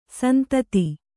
♪ santati